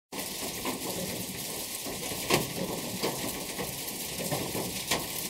HHCMC/rain4.ogg at resource-pack
rain4.ogg